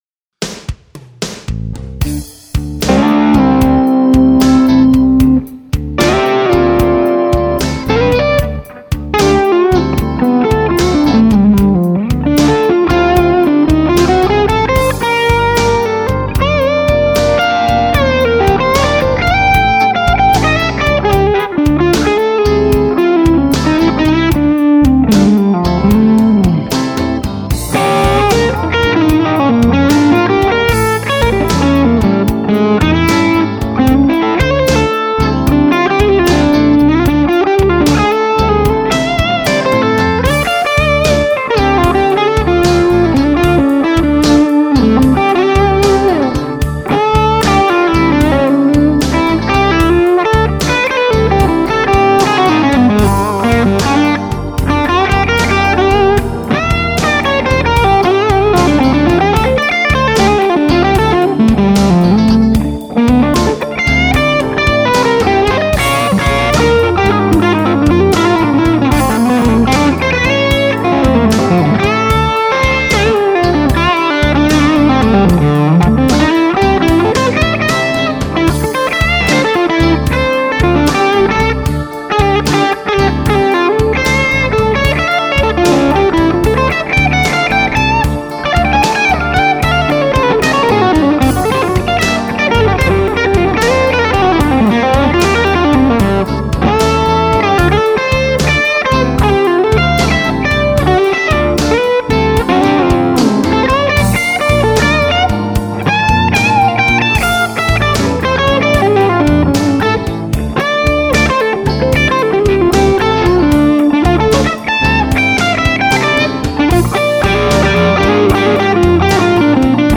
High Plate, Skyline Stack. Big ass bright cap on master. Loopalator and special cables. A little verb and delay added in mix. ANOS RCA in loopalator. A little clearer than the JJ I had in there. Amp has JJ ECC83S in all three slots. CSA on bridge humbucker.
G1265 speakers, multimiced with SM57 and R121 ribbon.
Arca-b.mp3 (done after listening to A, I made adjustments to mic positions)
I adjusted things a little after listening to clip A. I moved the mic a little, and also changed the blend between the SM57 and R121.
I love the top end bloom of the 2nd clip.
Me likey the 2nd clip best, more articulate, breathier highs.